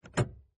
На этой странице собраны реалистичные звуки ручки двери: скрипы, щелчки, плавные и резкие повороты.
Звук захлопнутой двери